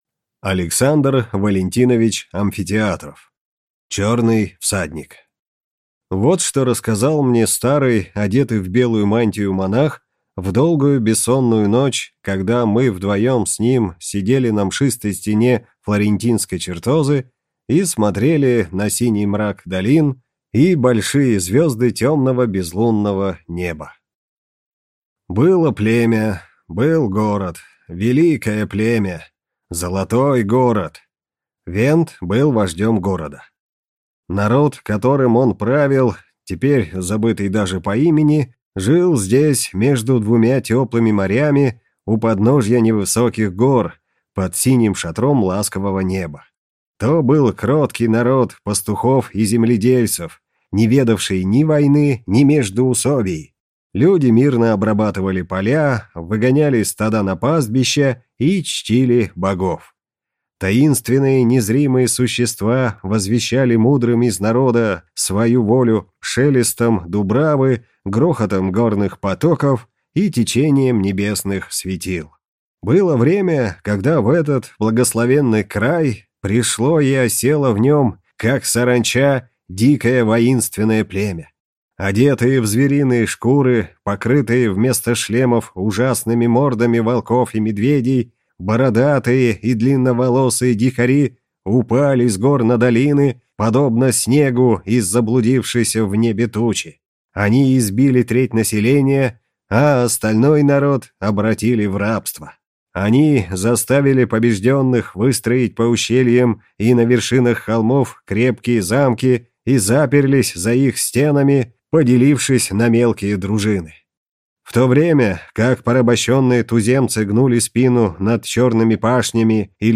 Аудиокнига Черный всадник | Библиотека аудиокниг